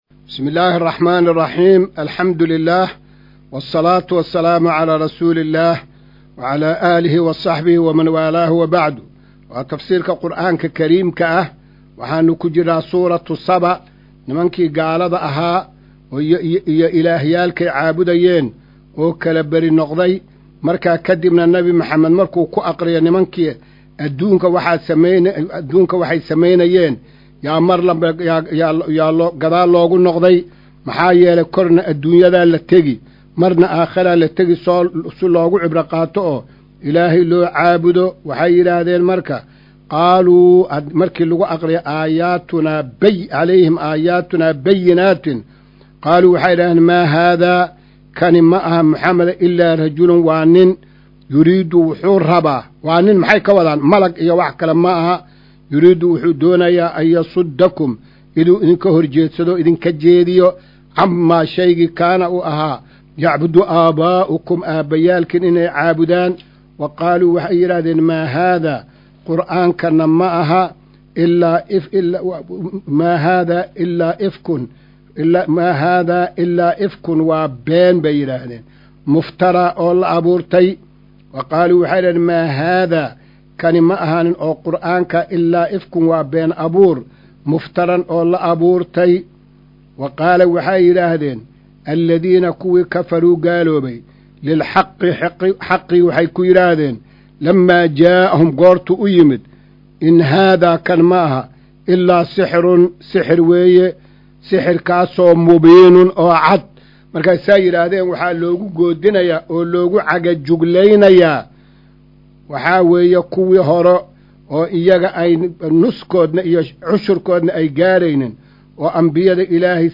Maqal:- Casharka Tafsiirka Qur’aanka Idaacadda Himilo “Darsiga 205aad”
Casharka-205aad-ee-Tafsiirka.mp3